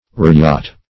Search Result for " ryot" : The Collaborative International Dictionary of English v.0.48: Ryot \Ry"ot\ (r[imac]"[o^]t), n. [Ar.